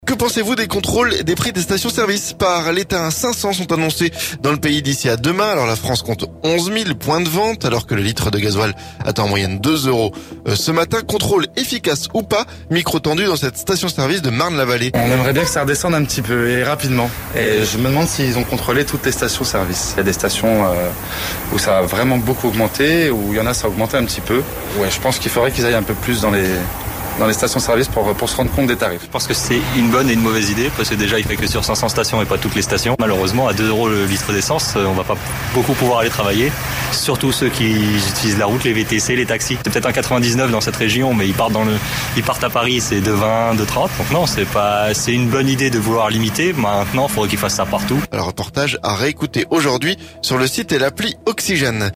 CARBURANT - Reportage dans une station-service de Marne-la-Vallée
Micron tendu dans cette station-service de Marne-la-Vallée.